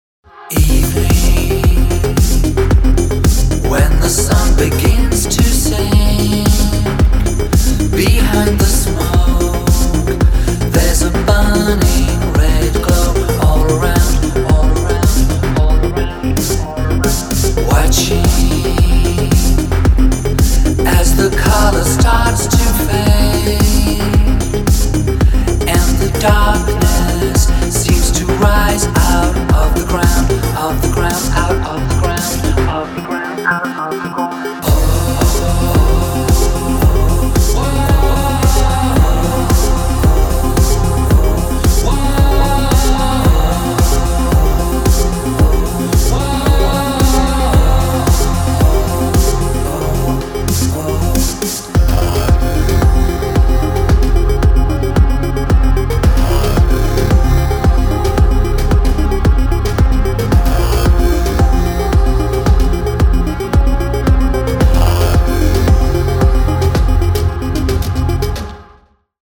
• Качество: 320, Stereo
Trance